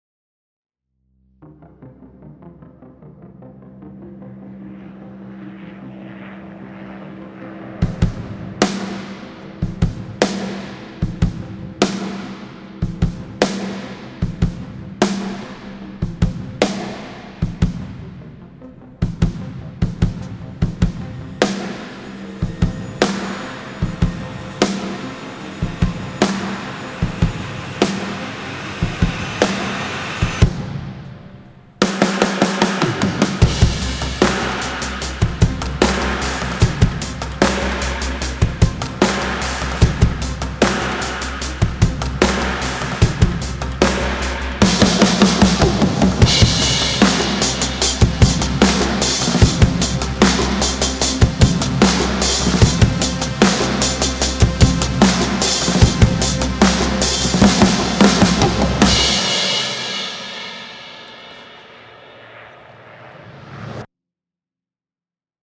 drum sample library